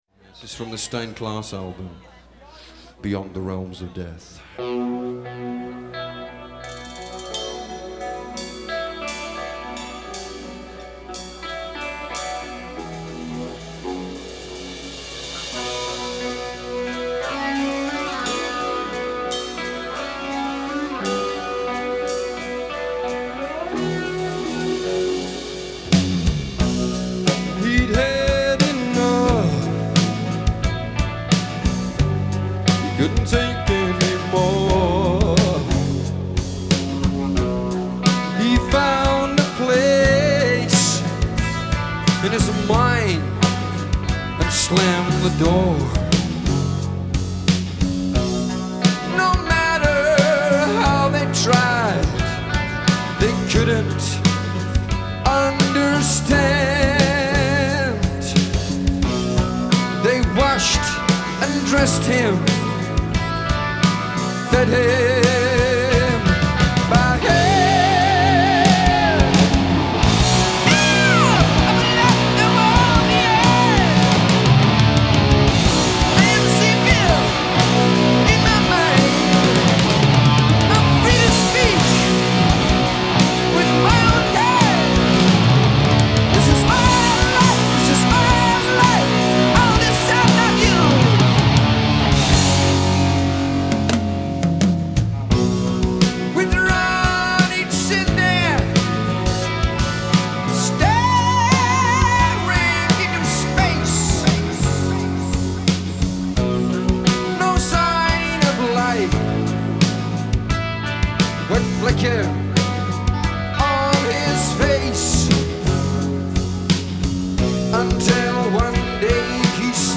Genre : Metal
Live at The Mudd Club, New York, 1979